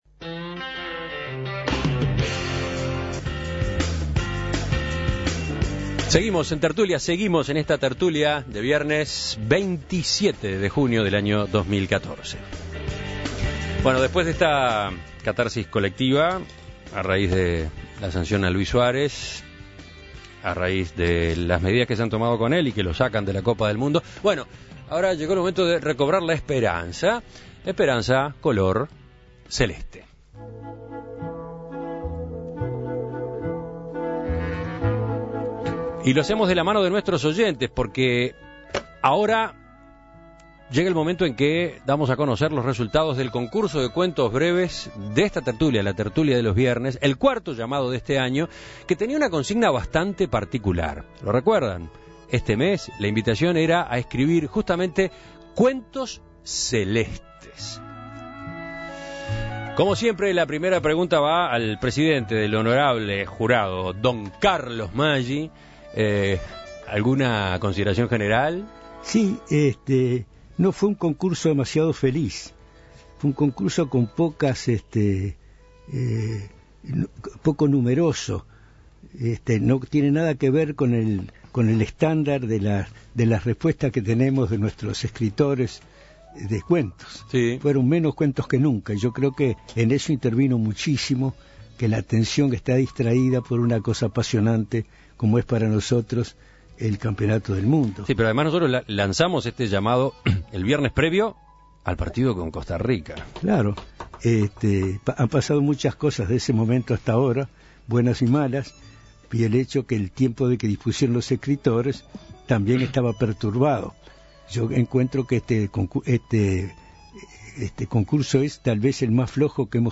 Cuentos leídos por los contertulios en esta cuarta edición del concurso de cuentos breves